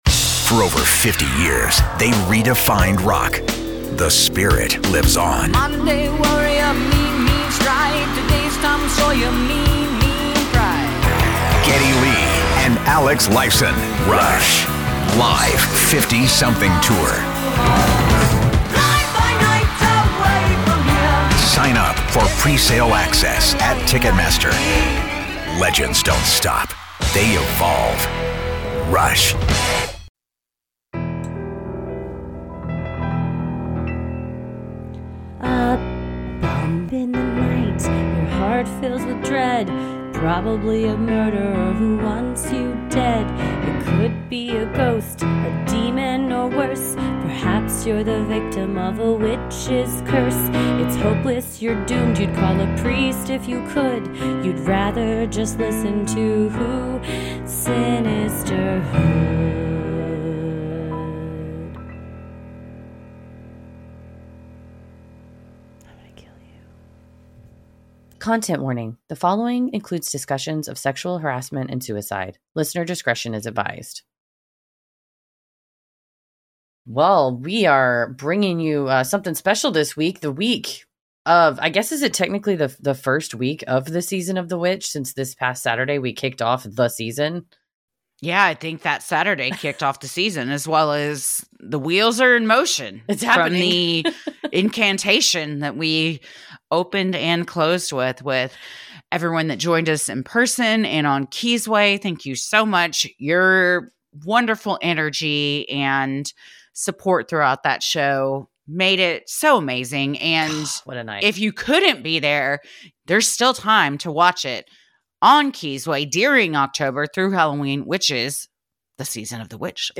Episode 364: A Conversation with John Quiñones